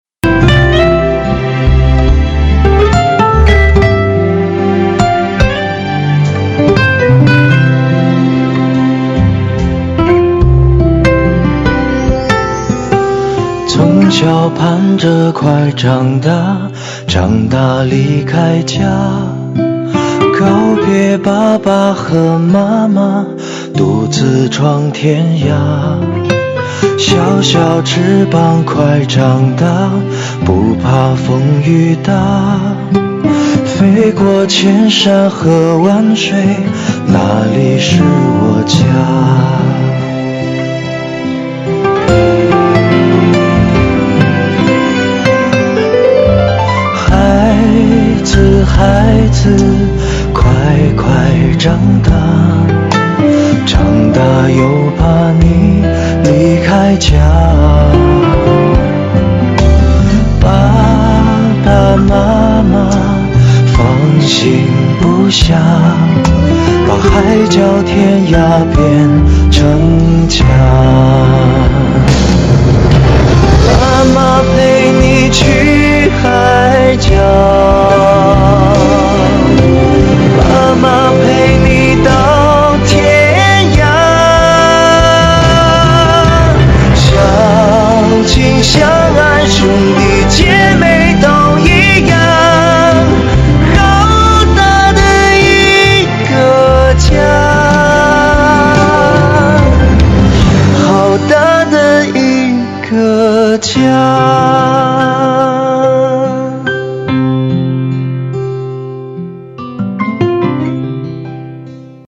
片尾曲